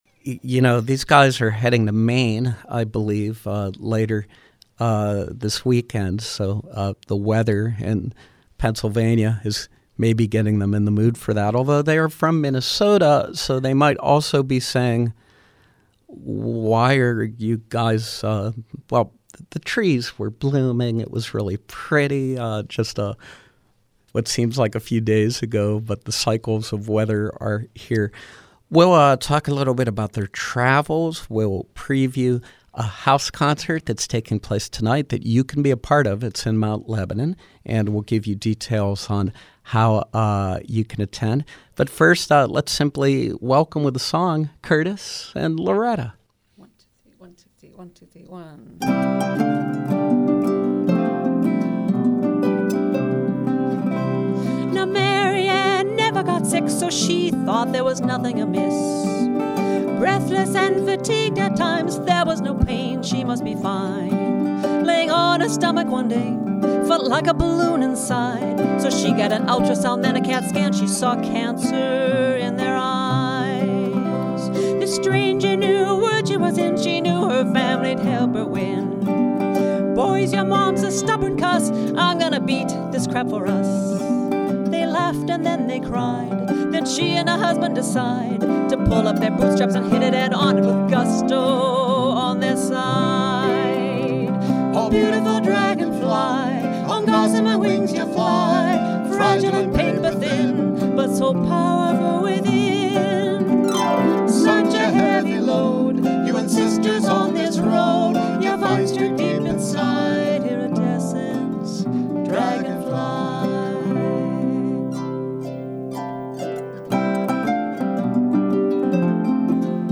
Minnesota-based folk duo